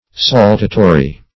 Saltatory - definition of Saltatory - synonyms, pronunciation, spelling from Free Dictionary
Saltatory \Sal"ta*to"ry\, a. [L. saltatorius. See Saltant, and